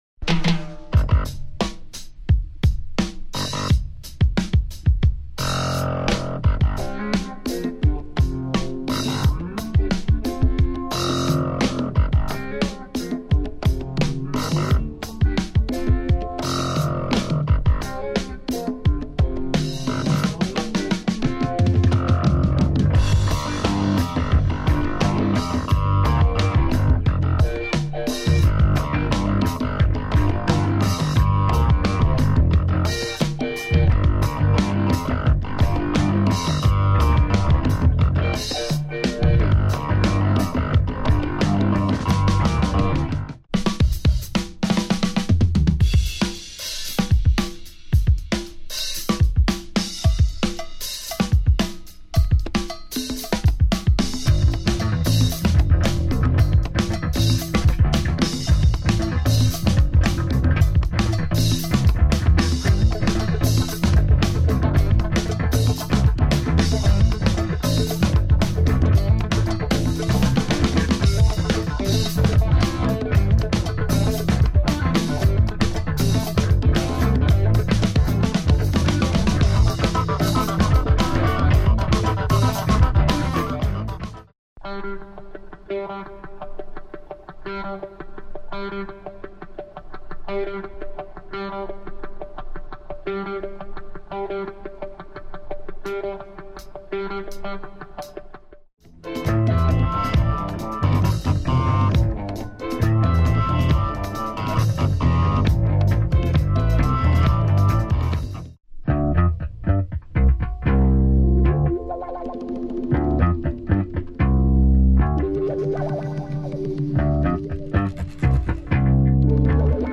two nice tracks with drumbreaks